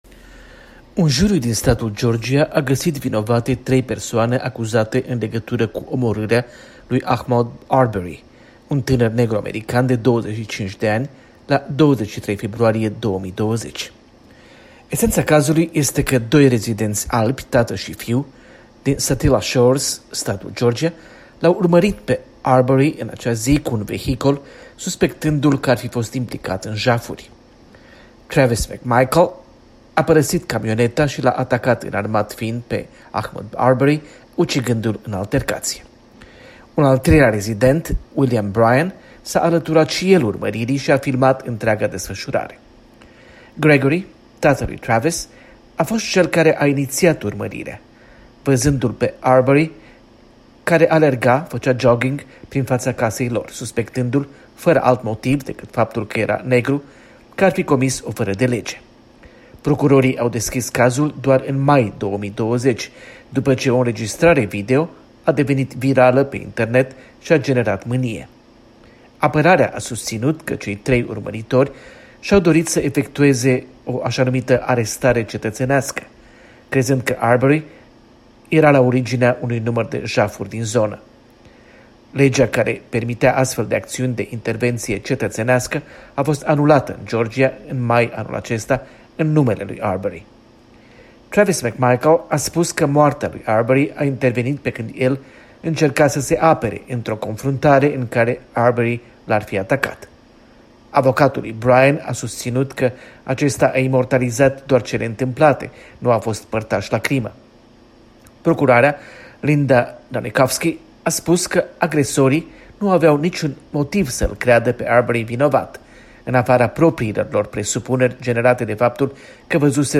Corespondenta de la Washington